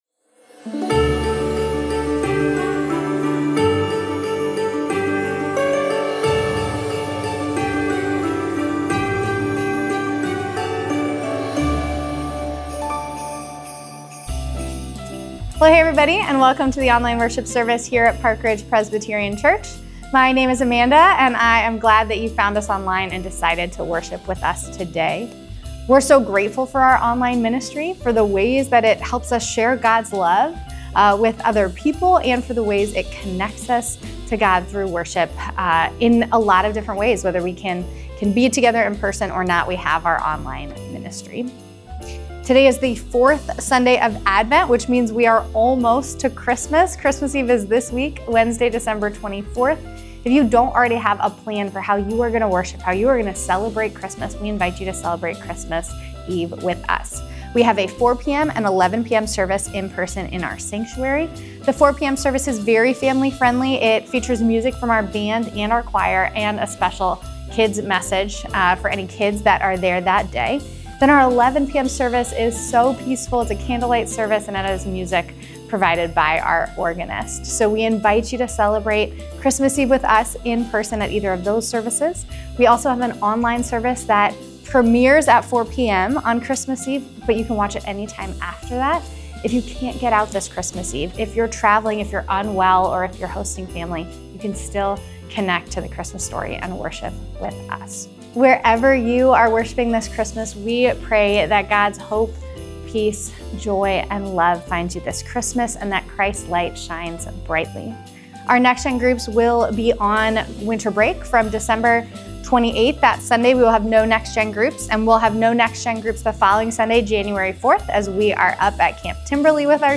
Welcome back our listeners, thank you for worshiping with us today!